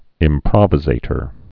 (ĭm-prŏvĭ-zātər)